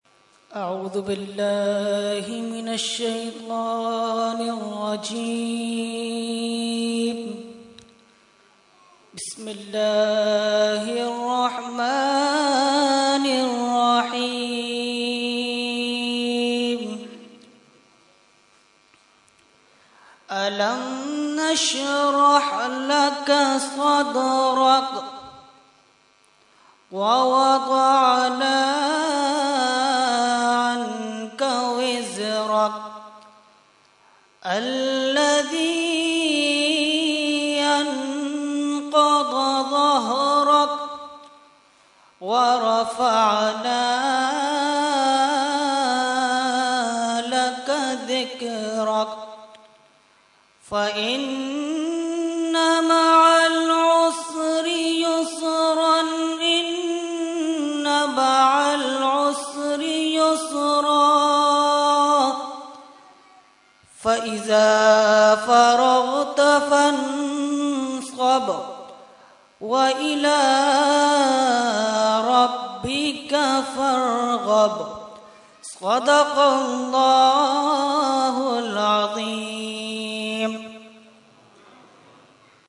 Category : Qirat | Language : ArabicEvent : 11veen Shareef 2016